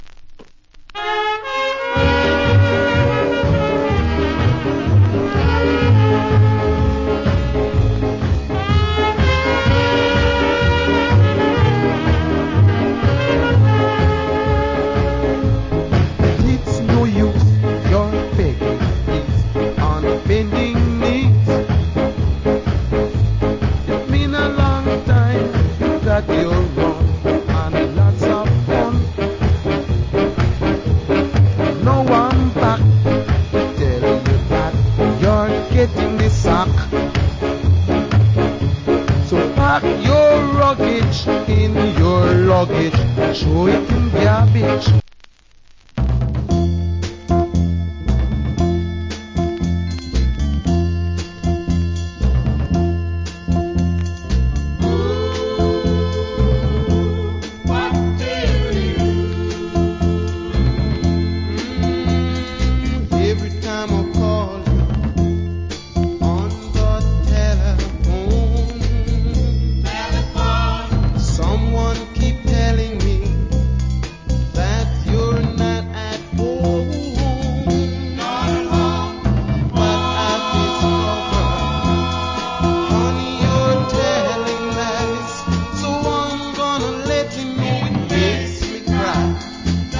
コメント Nice Ska Vocal.